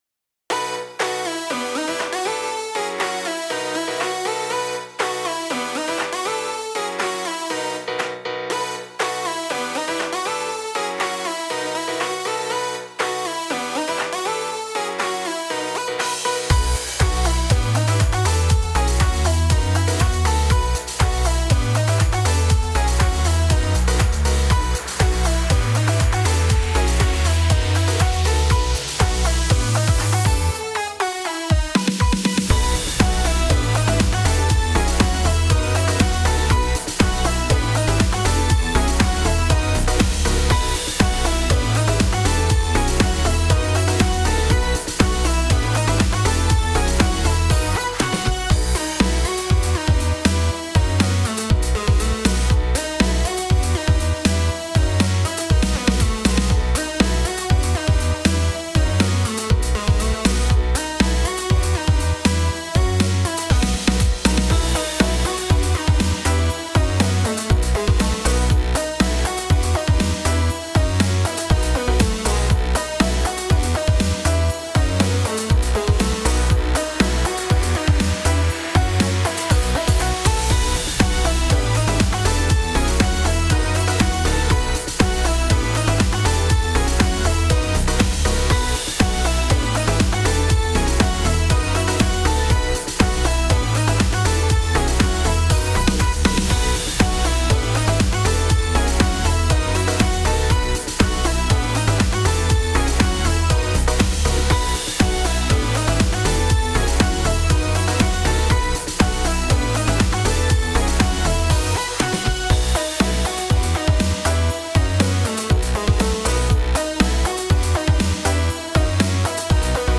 どこか不思議な雰囲気のある、かっこいいゲーム風BGMです
▶オリジナル版